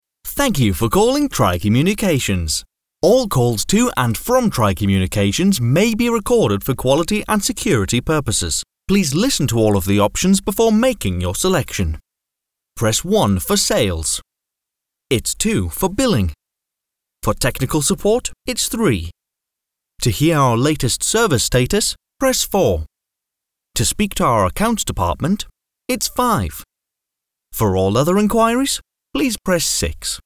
britisch
Sprechprobe: Sonstiges (Muttersprache):
Commercial, Identifiable, Bright, Intelligent, Clear, Entertaining, Eccentric.